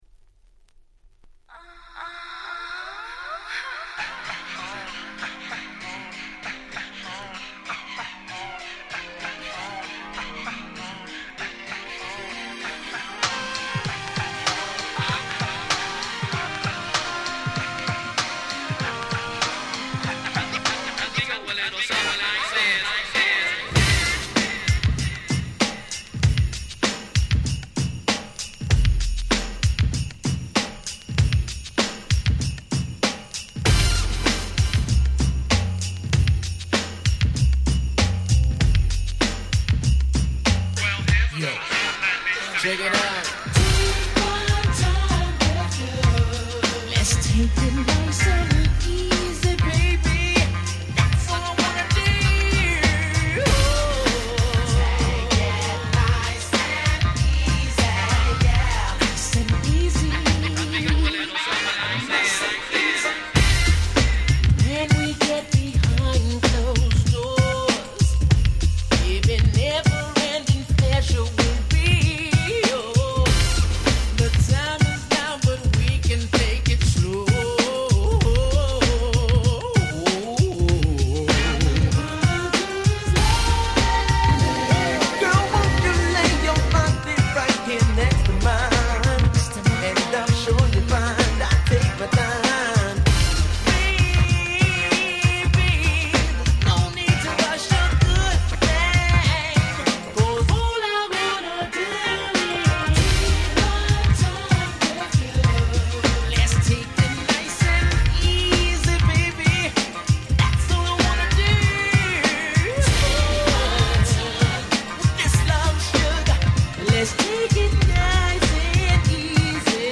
92' Nice R&B !!